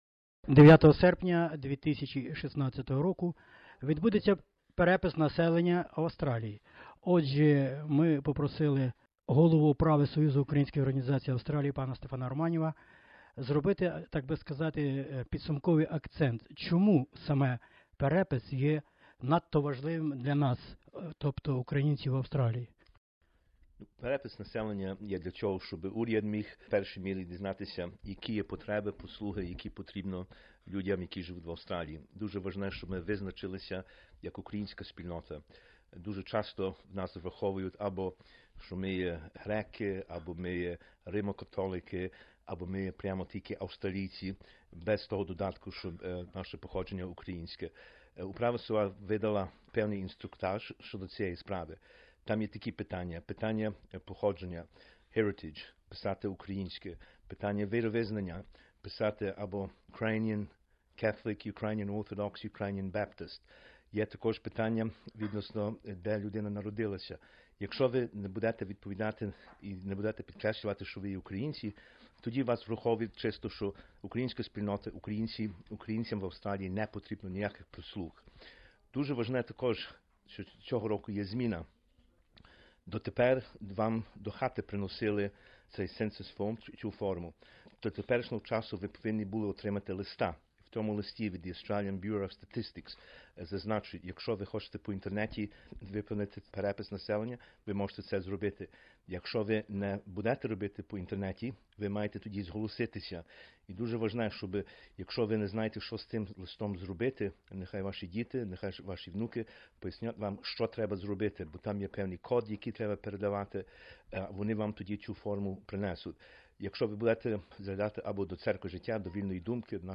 інтерв’ю